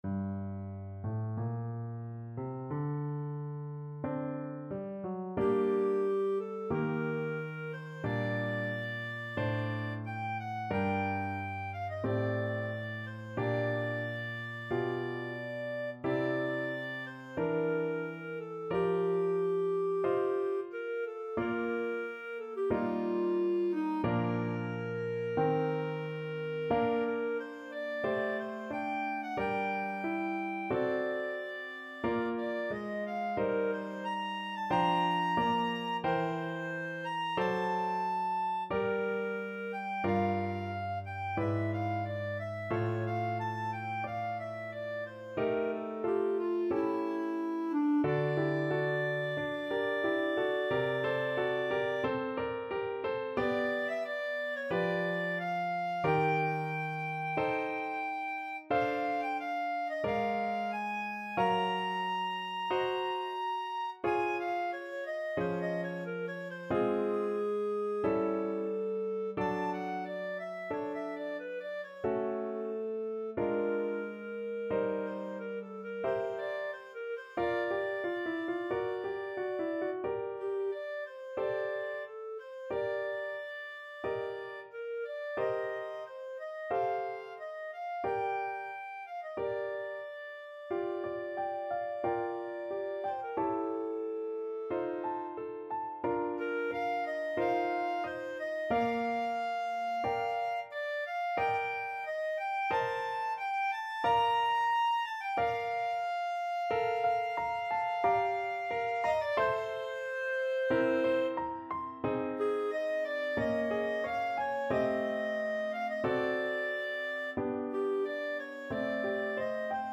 Clarinet
G minor (Sounding Pitch) A minor (Clarinet in Bb) (View more G minor Music for Clarinet )
Adagio, molto tranquillo (=60) =45
4/4 (View more 4/4 Music)
Classical (View more Classical Clarinet Music)